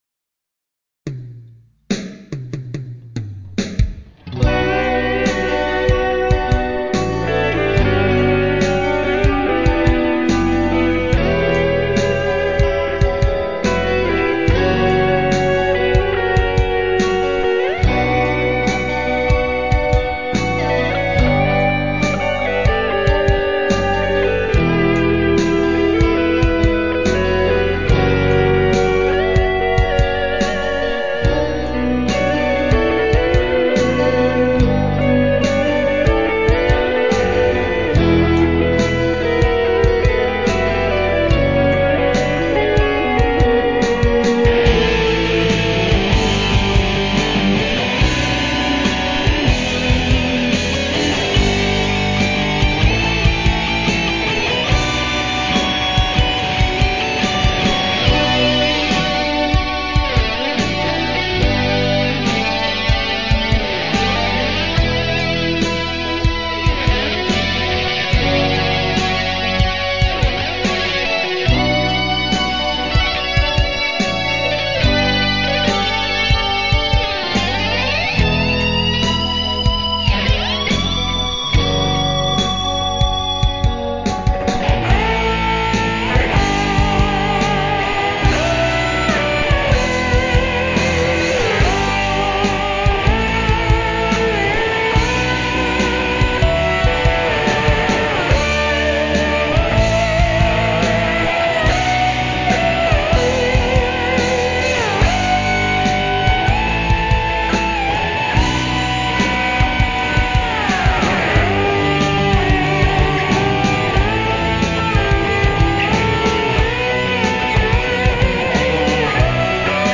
These were all done with other players I met thru Guitar War and we exchanged tracks over the web and put together some killer tunes!
Rhythm, bass & drums...Lead @ 1:23